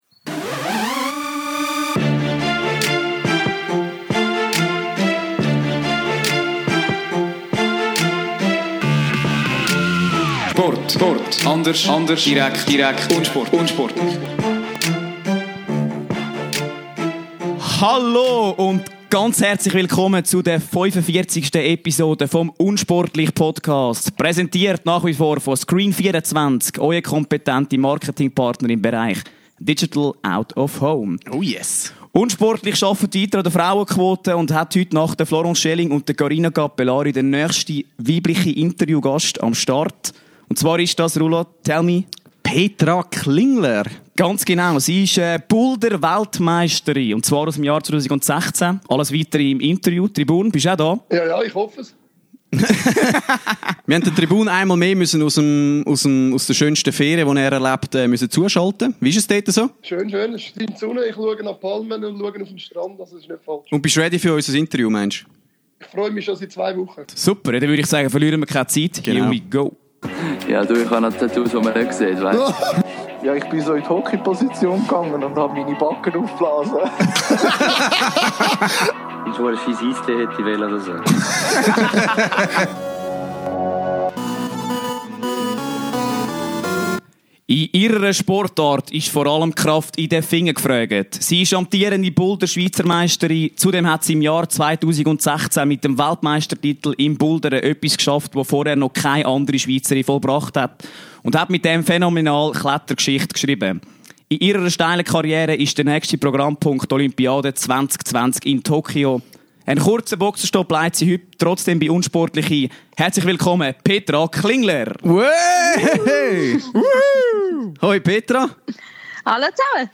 24.10.2018 – In Episode 45 präsentieren wir euch mit Petra Klinger den nächsten Interview-Hochkaräter und eine absolute Kletter-Kanone! Mit der mehr als sympathischen Boulder-Weltmeisterin sprechen wir über Marihuana im Klettersport, blutige Hobbit-Hände nach den Trainings und über Petras Vorstellungen des perfekten Mannes an ihrer Seite! Zudem offenbart uns Petra ihr Geheimrezept für schöne Fingernägel trotz ihres intensiven Sports an der Kletterwand!